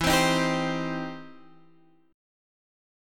Cm/E chord